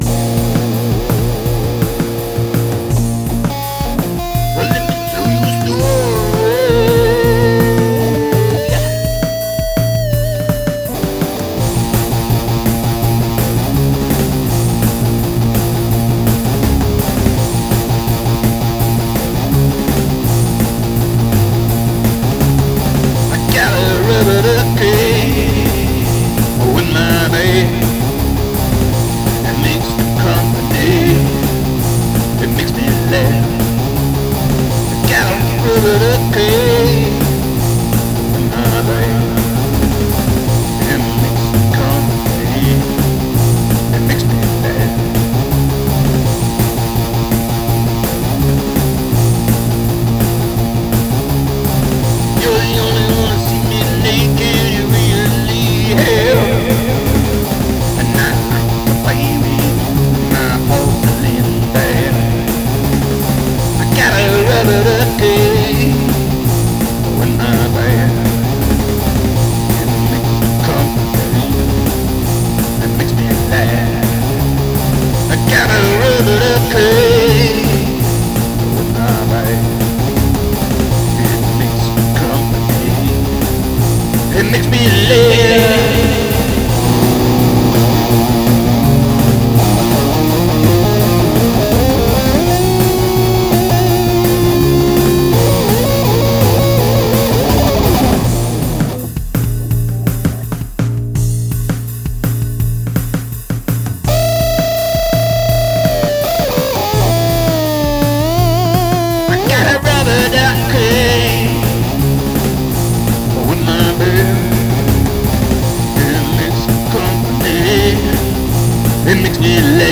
The relentlessness of those three discordant chords